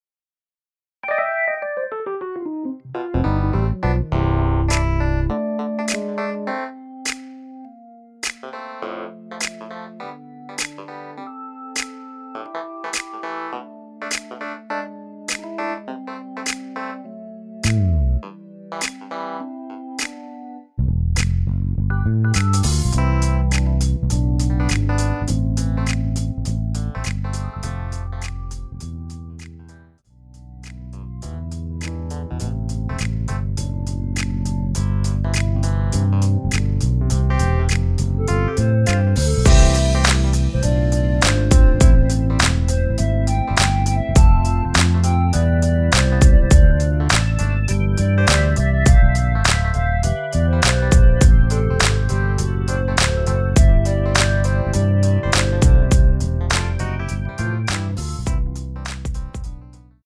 MR은 2번만 하고 노래 하기 편하게 엔딩을 만들었습니다.(본문의 가사와 코러스 MR 미리듣기 확인)
원키 멜로디 포함된 MR입니다.
앞부분30초, 뒷부분30초씩 편집해서 올려 드리고 있습니다.
중간에 음이 끈어지고 다시 나오는 이유는